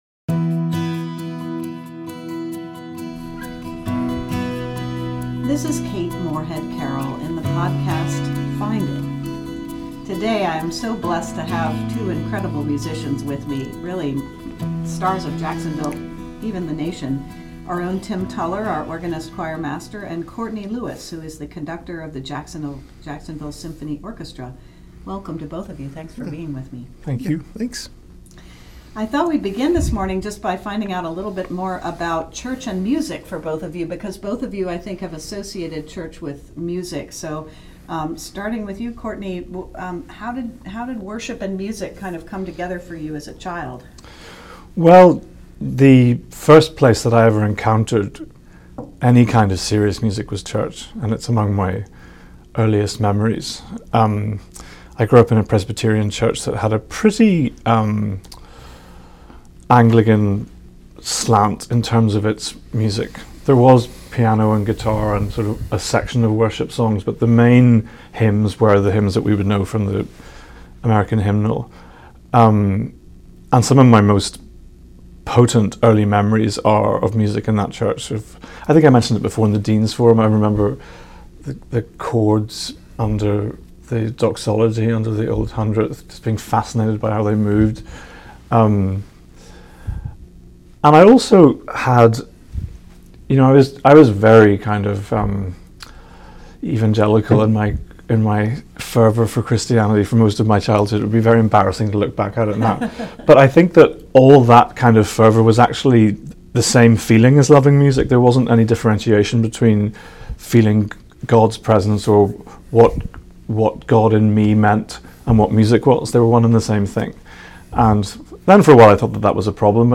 A Conversation about Sacred Music